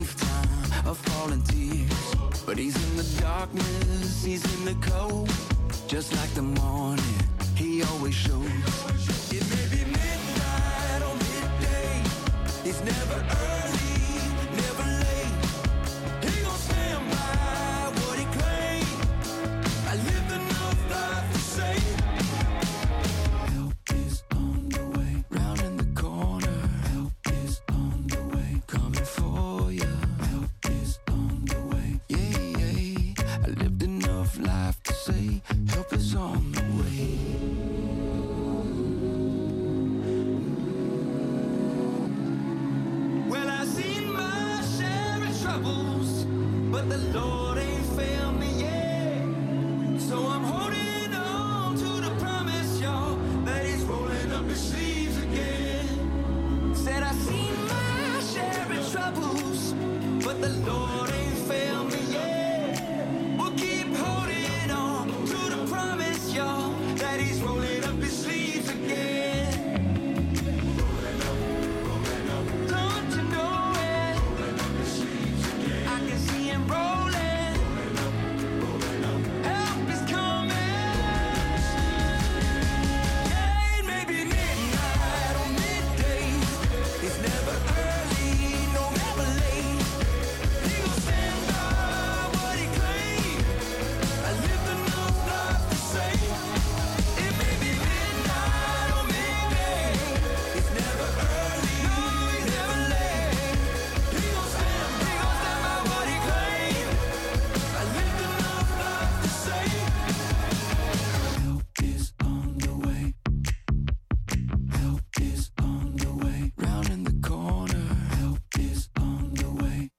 Night of Worship!